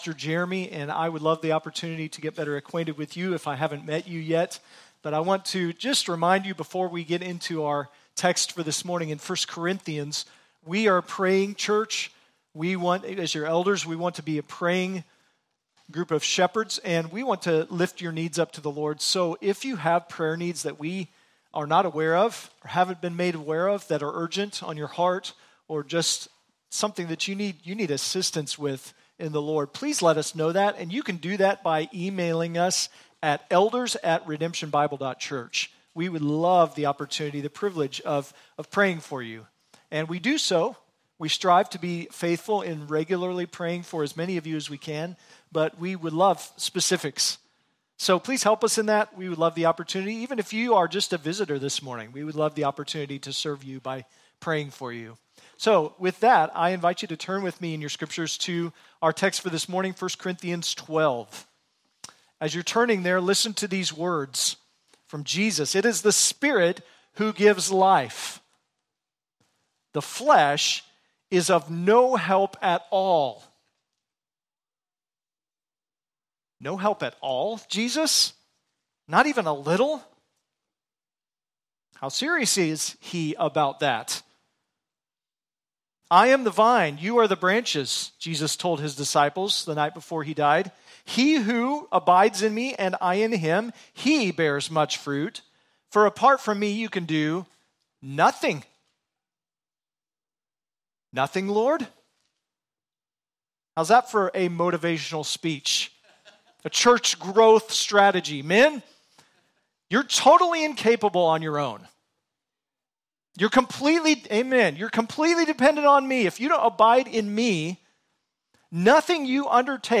Sermons | Redemption Bible Church